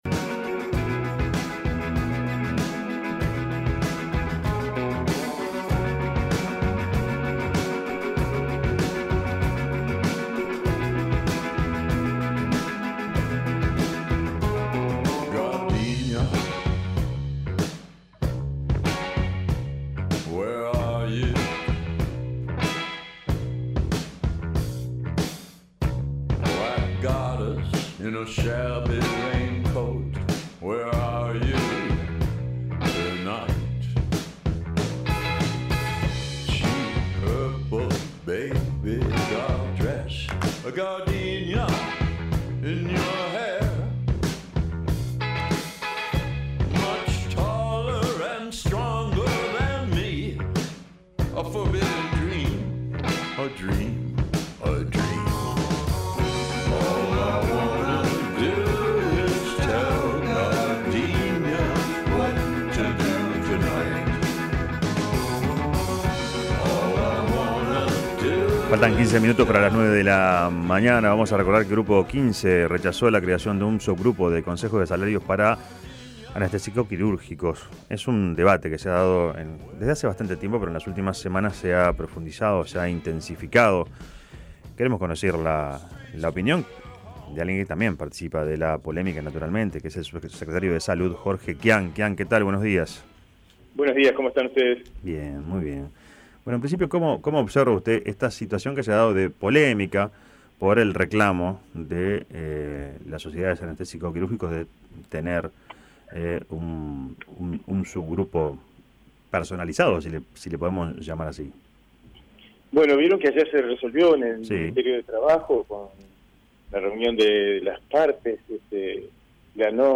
Quian manifestó en La Mañana de El Espectador que el Sindicato Médico no se puede fragmentar y que generar subgrupos especiales plantearía una situación de discusiones interminables.